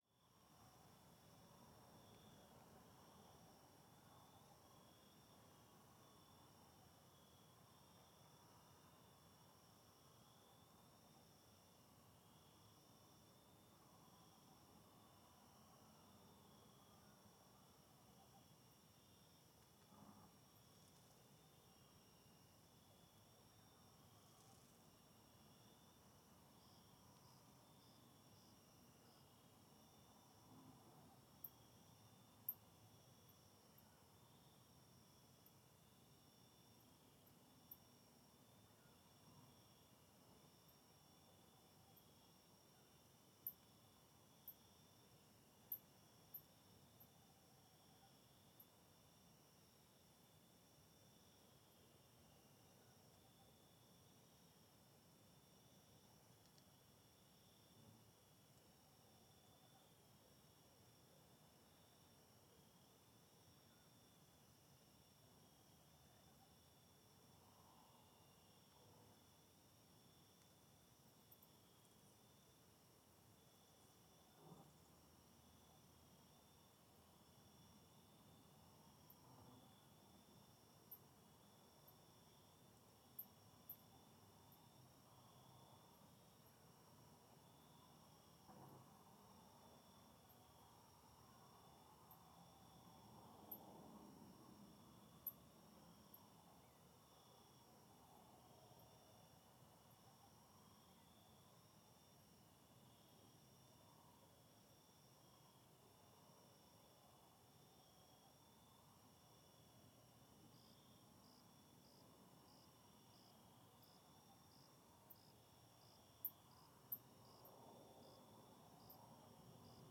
Arquivo de Ambientes Naturais - Coleção Sonora do Cerrado
CSC-05-069-GV - Ambiencia no Alto do Morro da Baleia com Ruidos da Rodovia ao Fundo e com Muitos Grilos.wav